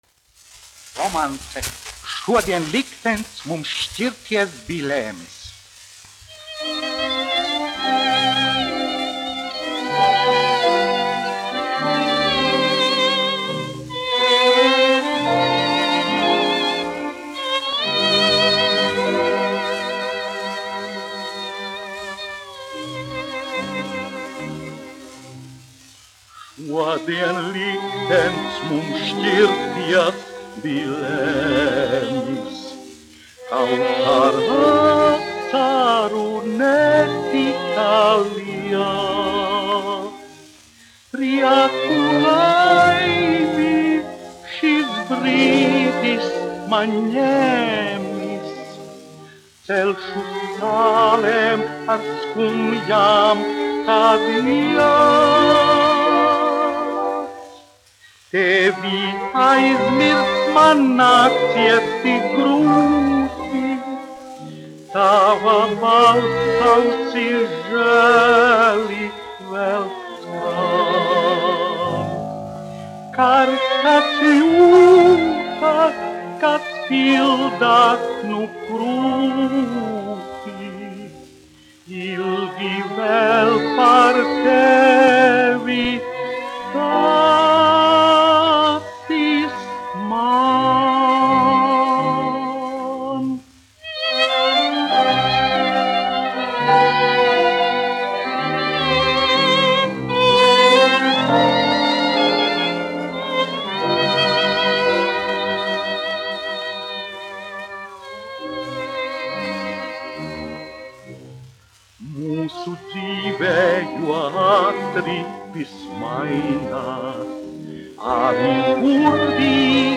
1 skpl. : analogs, 78 apgr/min, mono ; 25 cm
Romances (mūzika)
Latvijas vēsturiskie šellaka skaņuplašu ieraksti (Kolekcija)